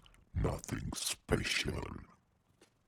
• demonic techno voice "nothing special".wav
Changing the pitch and transient for a studio recorded voice (recorded with Steinberg ST66), to sound demonic/robotic.